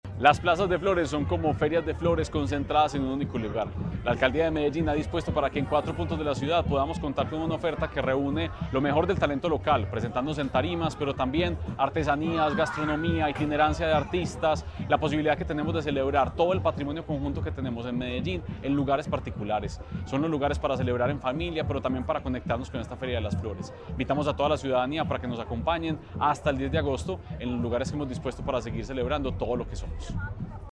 Declaraciones-secretario-de-Cultura-Ciudadana-Santiago-Silva-Jaramillo-2.mp3